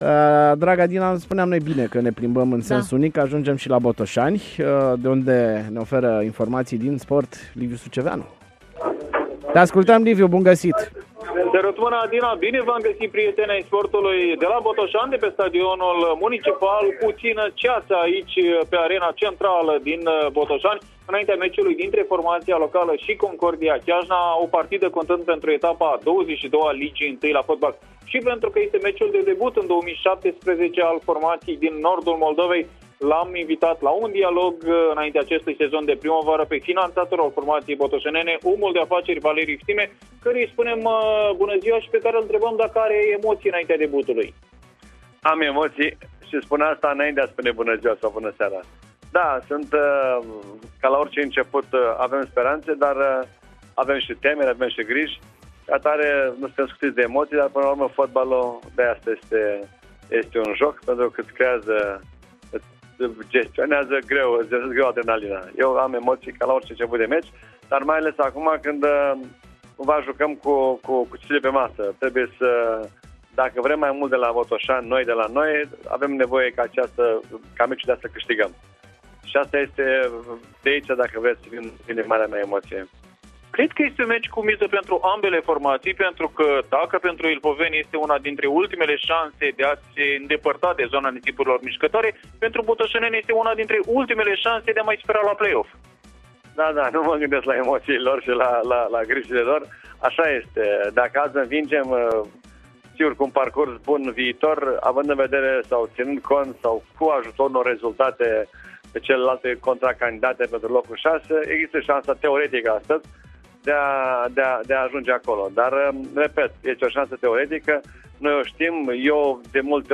Sport-blitz de pe stadionul „Municipal” din Botoşani